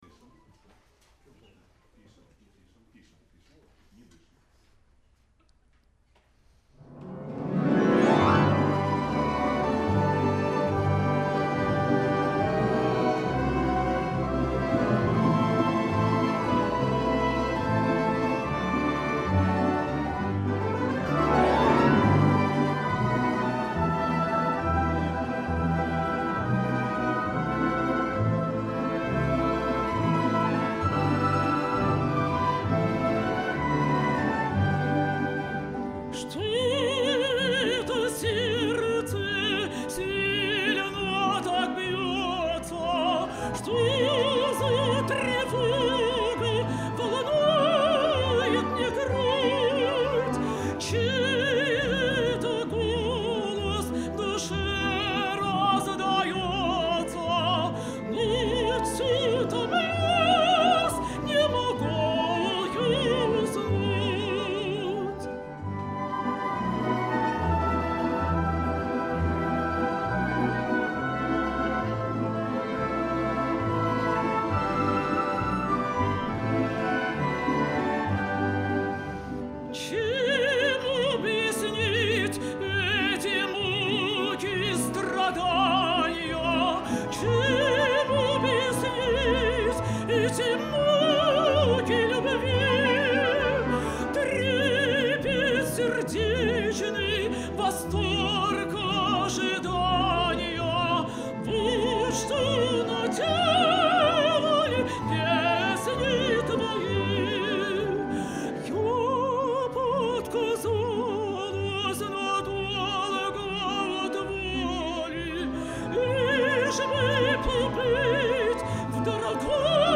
старинных русских романсов